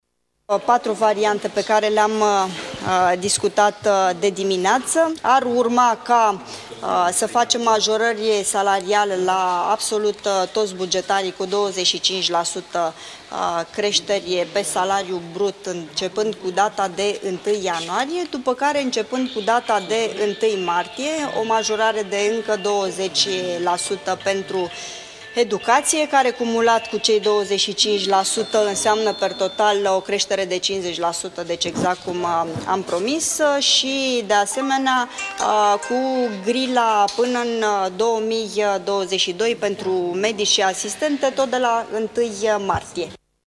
Din cauza amendamentelor făcute în Senat s-a ajuns la depăşirea bugetului, iar medicii şi profesorii ar urma să primească creşterile salariale promise cu două luni mai târziu, a anuntat astăzi ministrul muncii, Lia Olguţa Vasilescu: